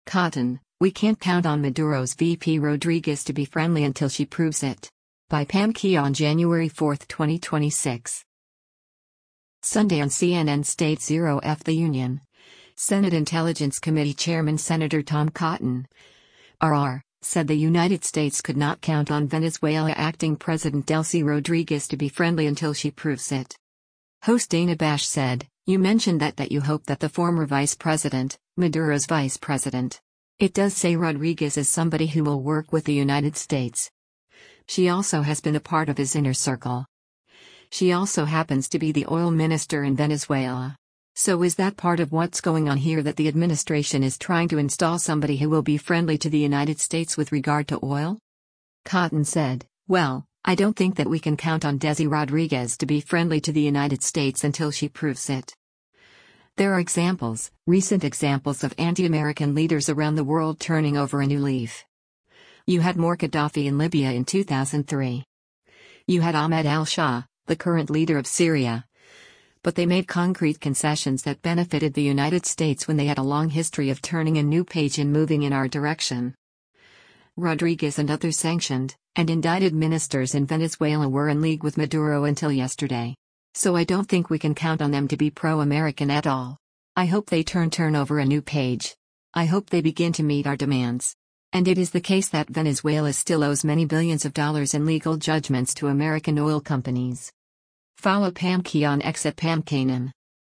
Sunday on CNN’s “State 0f the Union,” Senate Intelligence Committee chairman Sen. Tom Cotton (R-AR) said the United States could not count on Venezuela acting president Delcy Rodríguez to be friendly “until she proves it.”